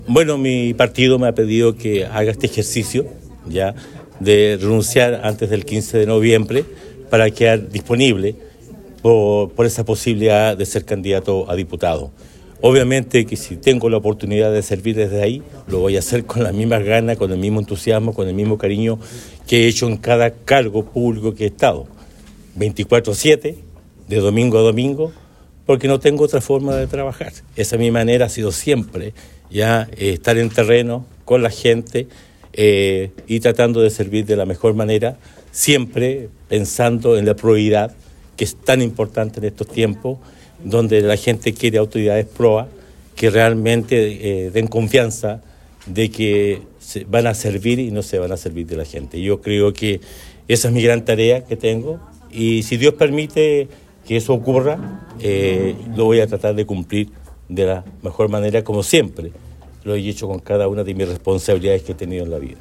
En sesión ordinaria de Concejo Municipal realizada este martes 12 de noviembre, el alcalde de Osorno, Emeterio Carrillo Torres, presentó la renuncia a su cargo, la que se hará efectiva a contar del próximo sábado 16 de noviembre.